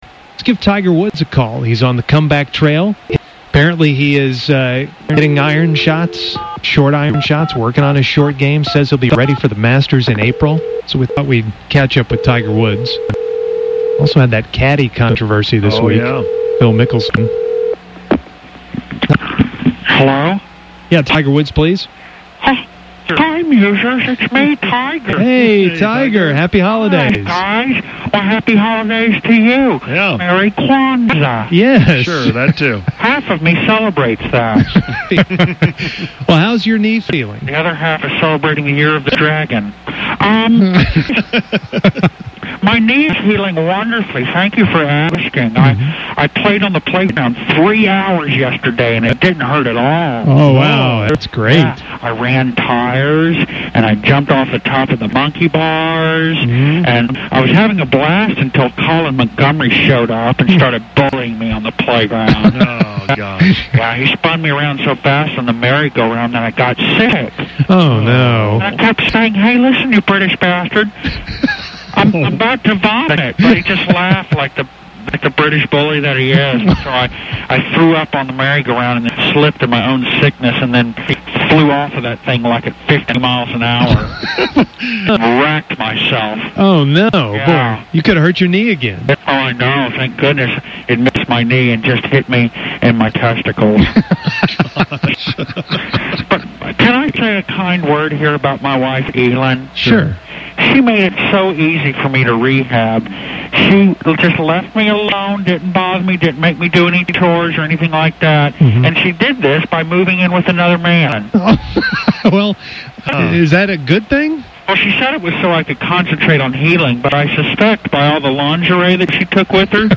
It’s been a while since we’ve heard fake Tiger on the radio.